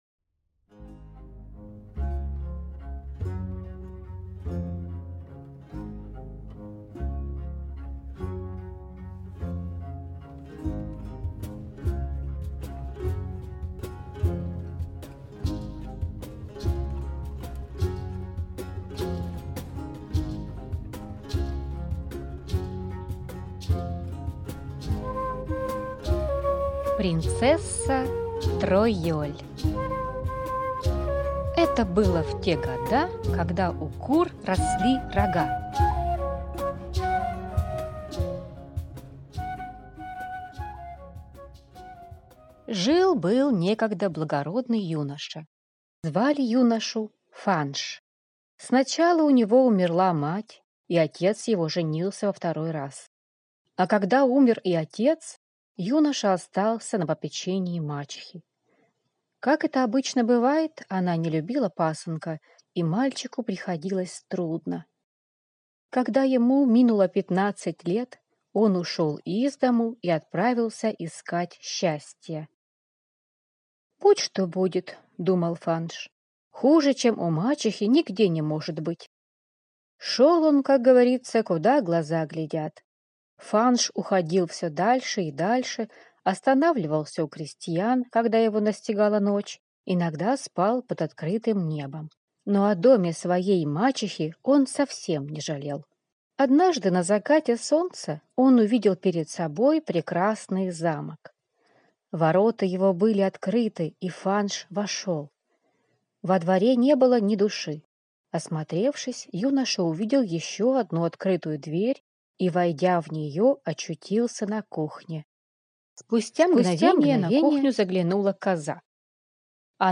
Принцесса Тройоль - французская аудиосказка - слушать онлайн